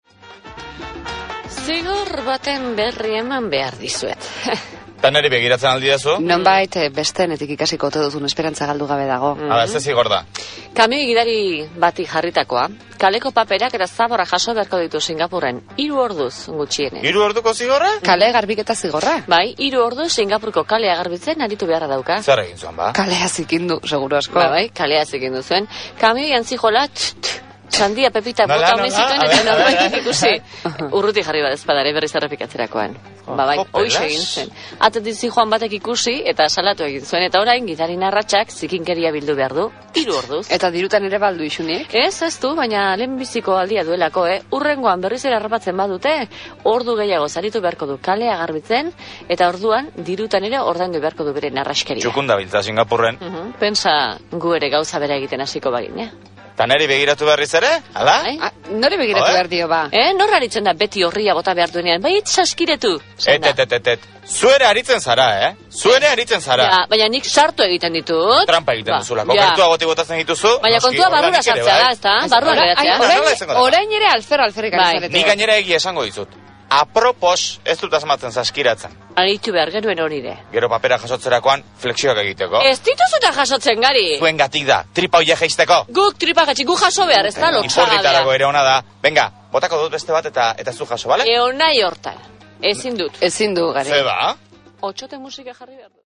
Entzungaian bi pertsona (neska eta mutila) ari dira berriaren inguruan. Neskak emango du eta zenbaitetan bai neskak bai mutilak komentarioak egingo dituzte ematen ari den berriaren inguruan.